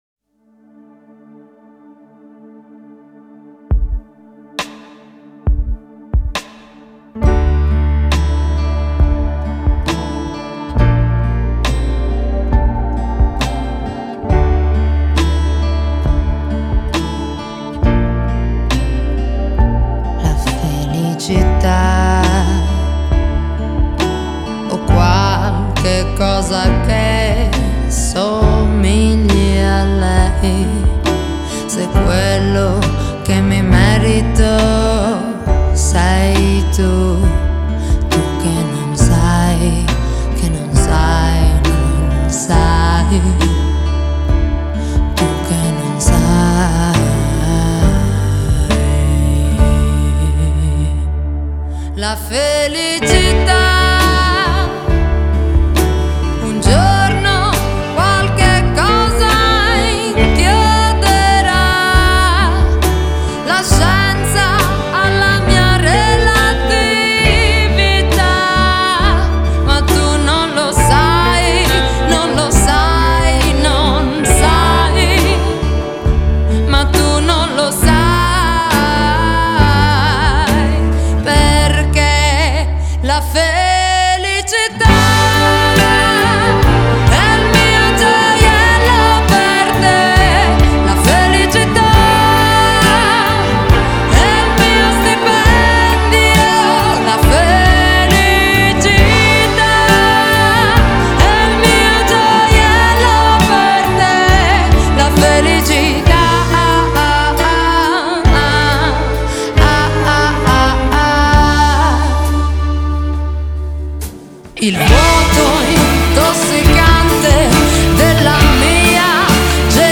Genre: Pop, Soul